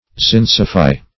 zincify - definition of zincify - synonyms, pronunciation, spelling from Free Dictionary Search Result for " zincify" : The Collaborative International Dictionary of English v.0.48: Zincify \Zinc"i*fy\, v. t. [Zinc + -fy.]